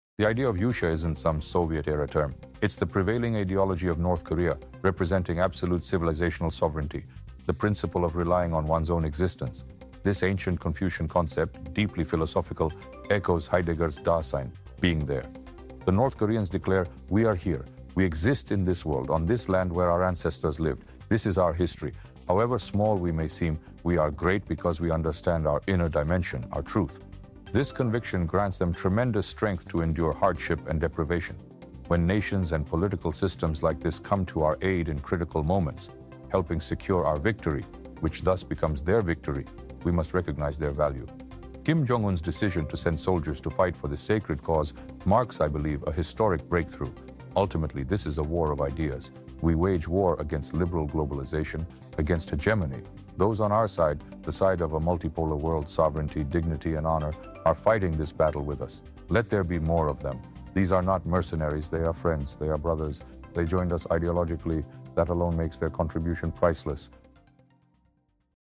North Korea is a small country, but it has proven itself to be a GREAT NATION, AI Dugin explains.